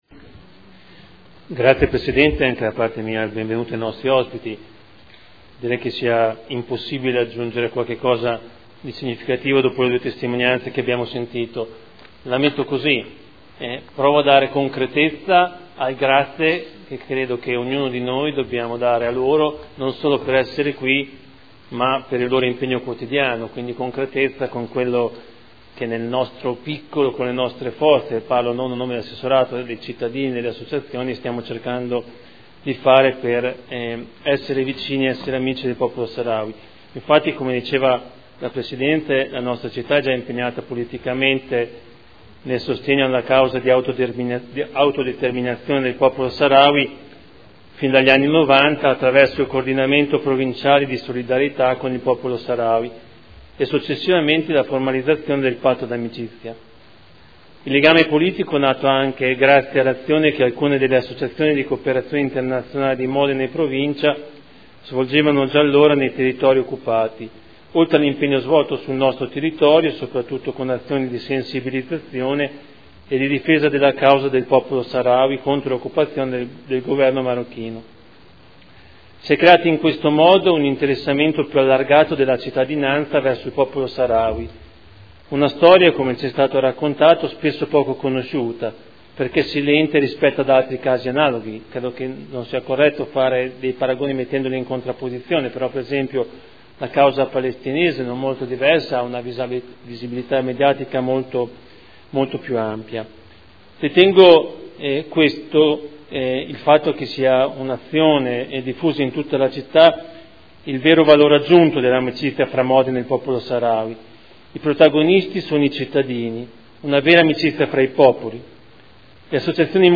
Seduta del 21/11/2013 Solidarietà al Popolo Saharawi in occasione del 38° EUCOCO Intervento dell'assessore Fabio Poggi e votazione